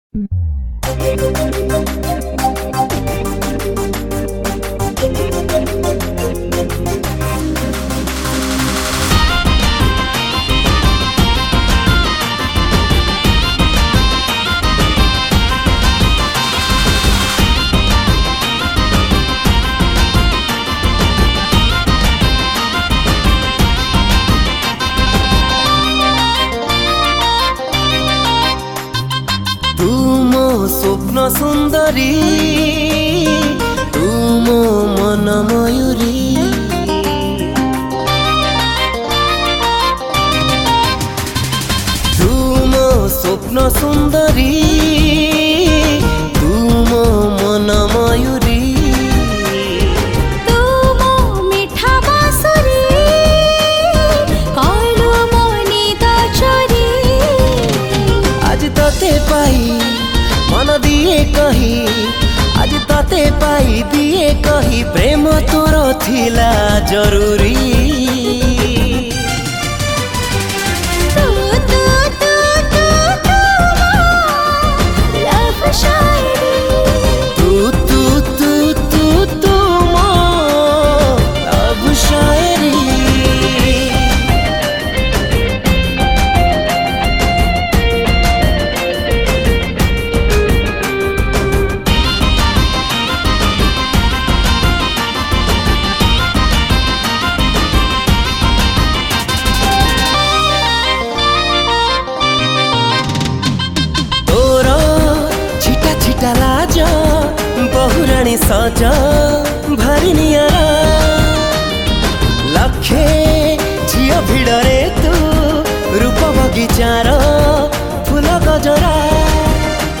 (Studio Version)